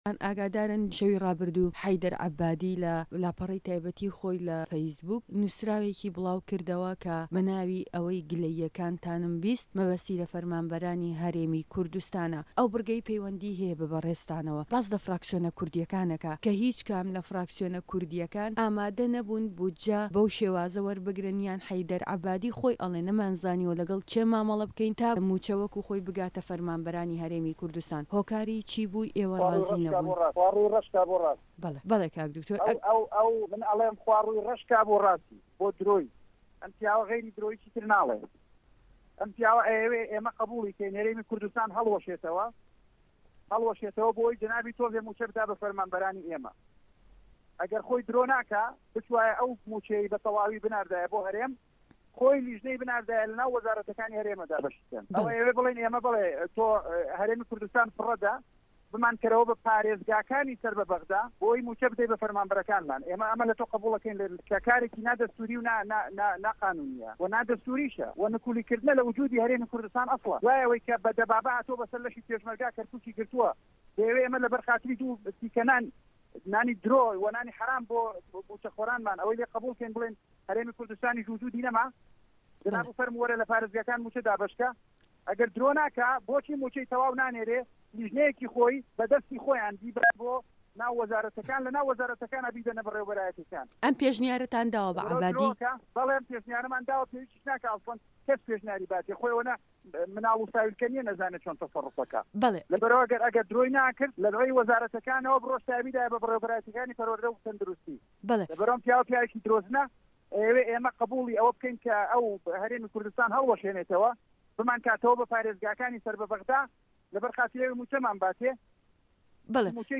ده‌قی گفتوگۆكه‌ی